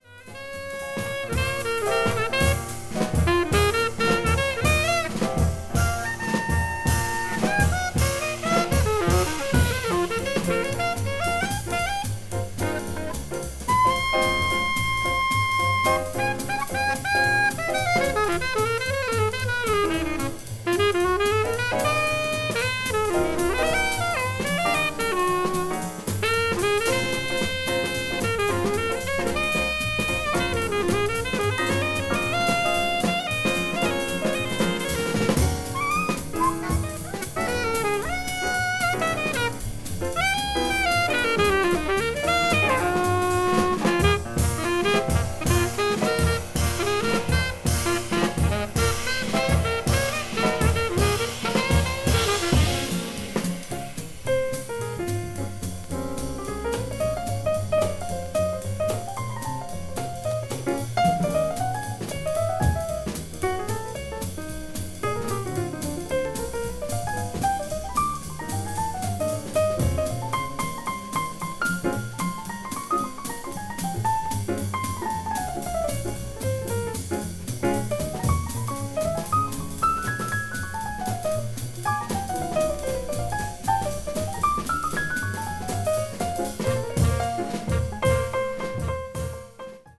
alto sax
piano
bass
drums
characterized by a bright, clear tone and melodic phrasing
blues jazz   cool jazz   hard bop   modern jazz